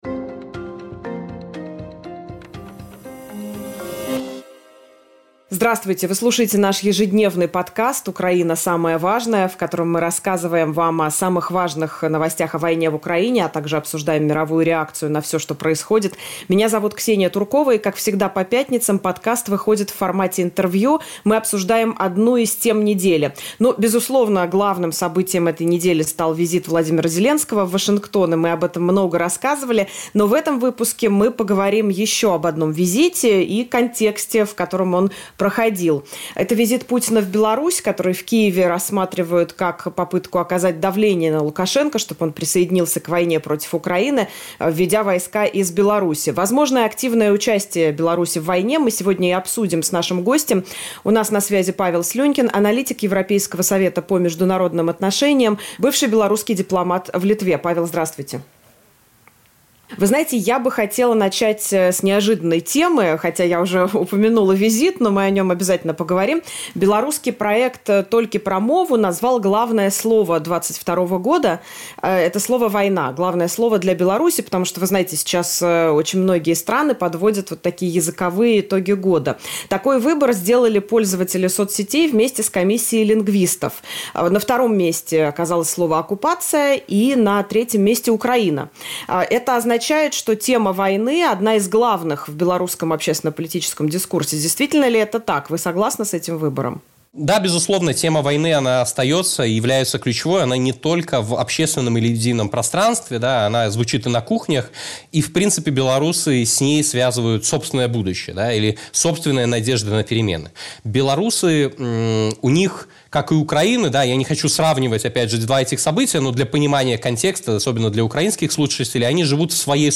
Украина. Самое важное. Роль Беларуси в войне (интервью)